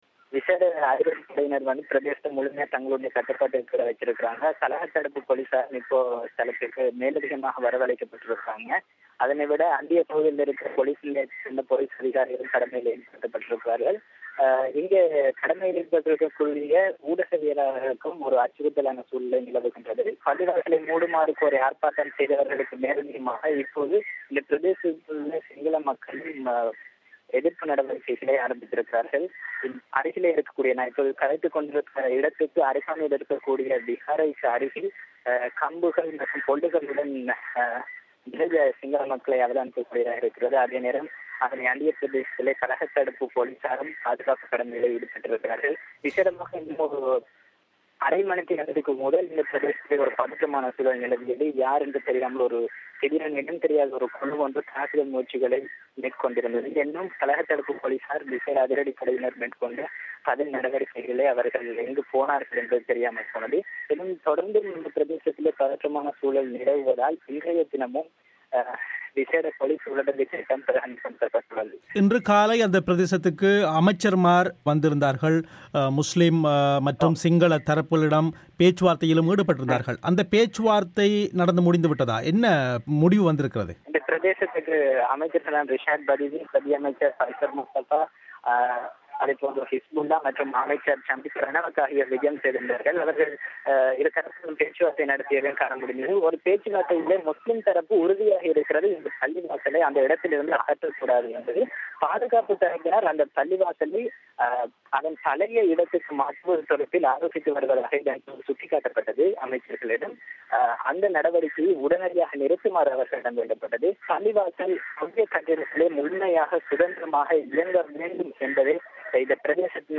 சம்பவ இடத்திலிருந்து உள்ளூர் ஊடகவியலாளர் ஒருவர் பிபிசி தமிழோசைக்கு அளித்த நேரடித் தகவல்